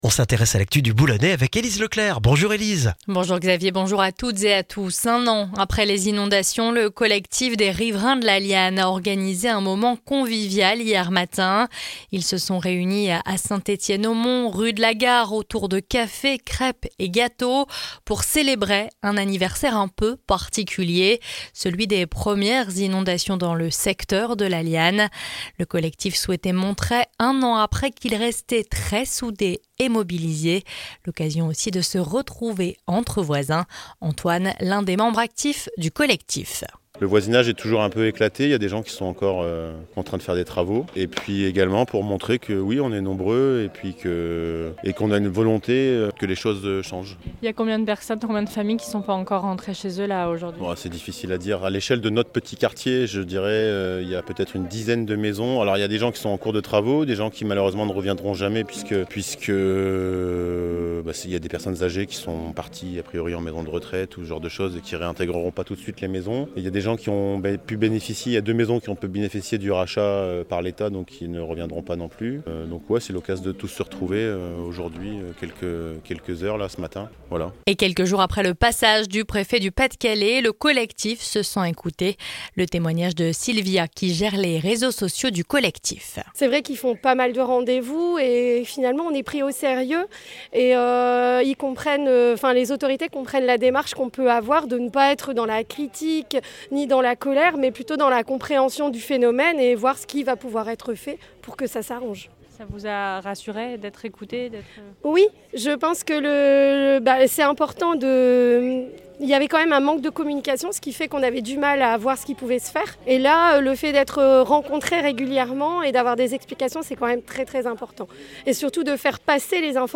Le journal du lundi 4 novembre dans le Boulonnais